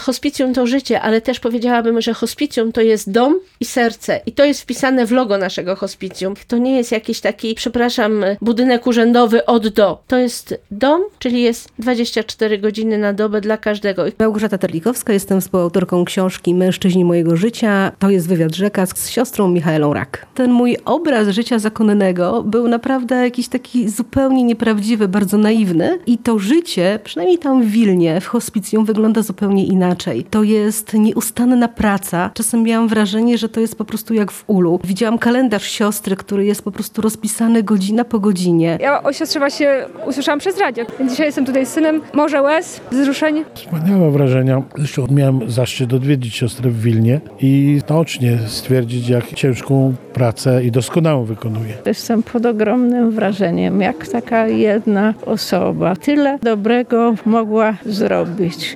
Spotkanie autorskie